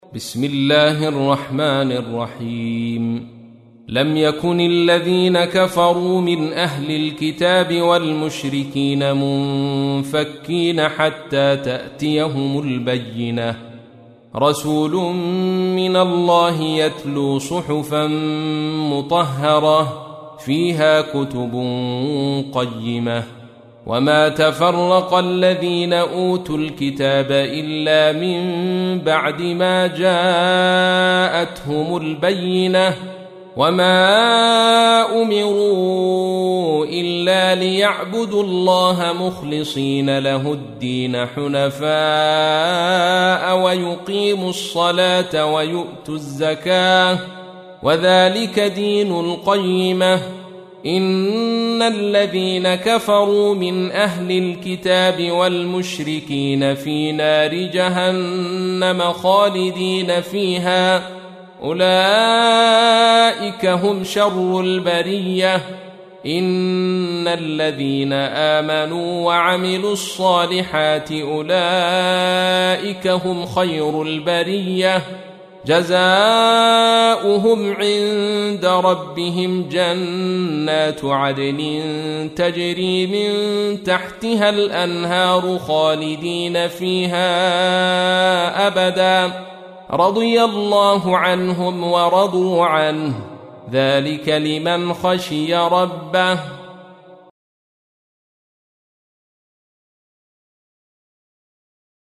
تحميل : 98. سورة البينة / القارئ عبد الرشيد صوفي / القرآن الكريم / موقع يا حسين